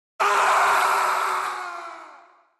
Dark Souls/Bloodborne death SFX, for the meme.